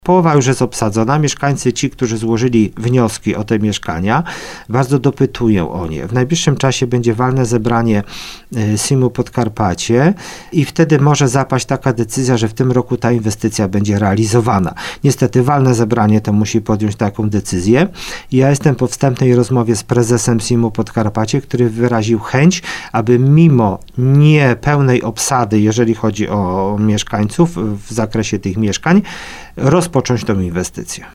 Jak mówił w Słowo za Słowo wójt gminy Wietrzychowice Robert Śpiewak, były już prowadzone rozmowy z inwestorem, który wstępnie zapewnił, że inwestycja ruszy mimo niepełnego obłożenia.